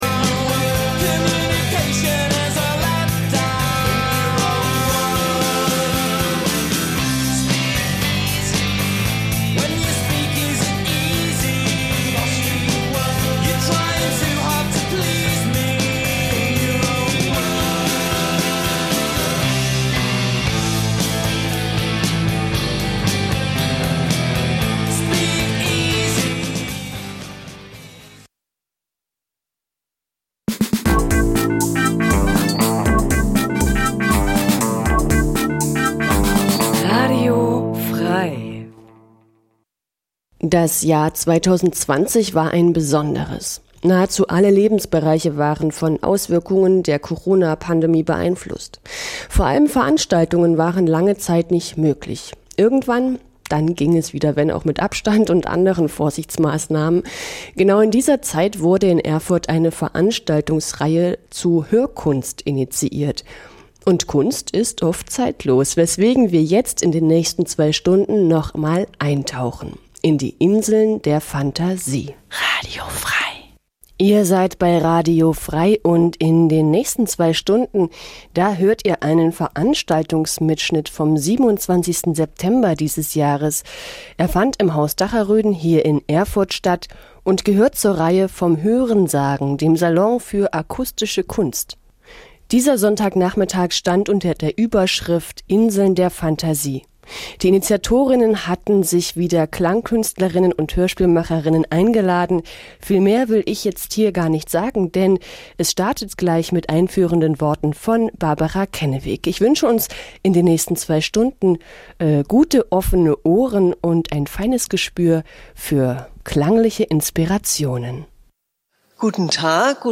VomH�renSagen - Salon f�r akustische Kunst Ihr h�rt eine Aufzeichnung vom 27. September 2020 (Haus Dacher�den)